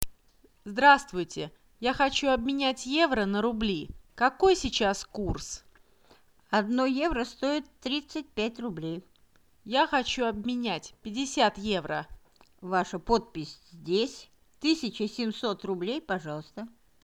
Kuuntele ensin keskustelu ja vastaa sitten kysymyksiin.
Takaisin Ostoksilla 2 osioon => Seuraava tilanne В пункте обмена валюты 1 Kuuntele keskustelu rahanvaihtopisteessä Kuuntele ensin keskustelu ja vastaa sitten kysymyksiin.